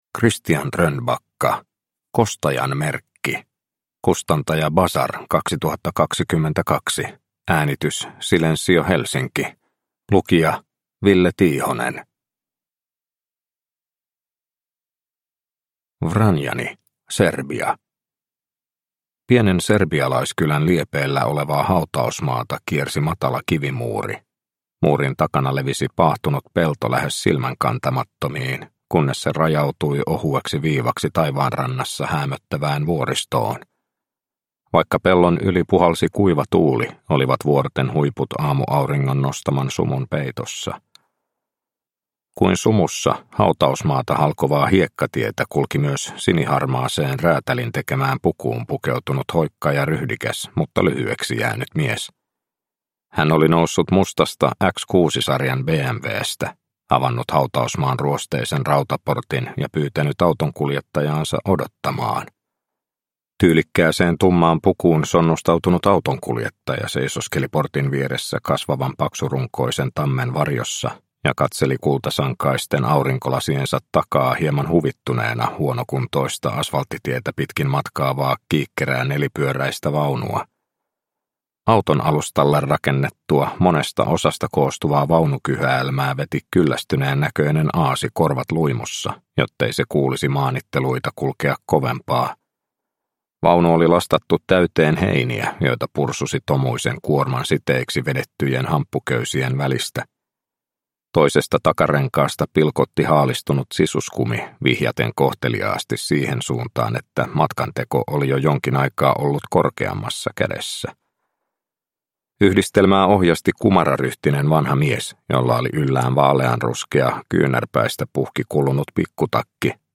Kostajan merkki – Ljudbok – Laddas ner